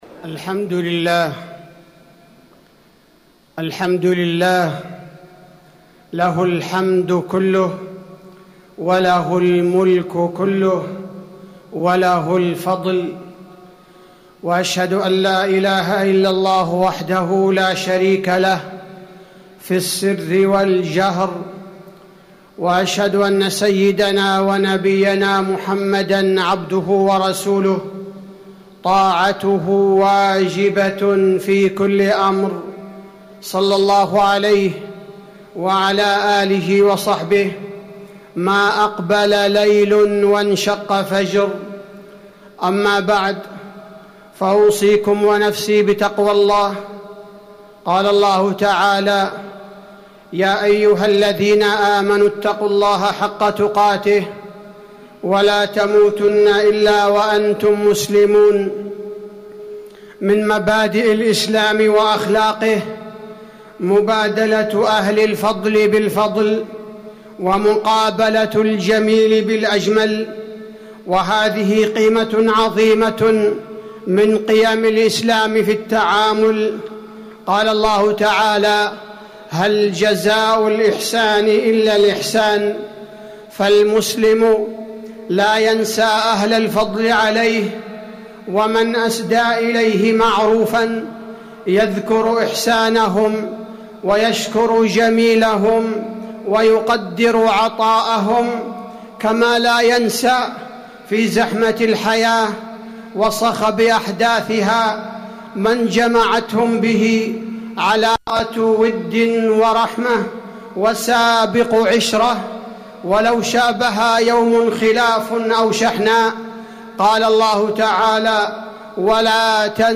تاريخ النشر ٢٩ شوال ١٤٣٩ هـ المكان: المسجد النبوي الشيخ: فضيلة الشيخ عبدالباري الثبيتي فضيلة الشيخ عبدالباري الثبيتي مكافأة أهل الفضل